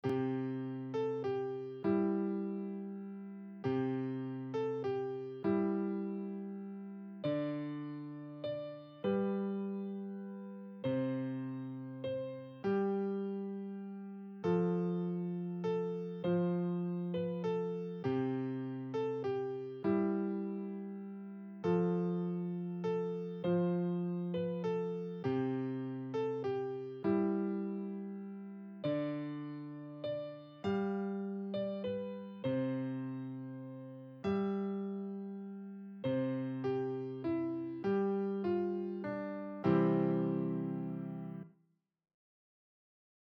Piano
Douce Nuit - Piano Débutant 100bpm.mp3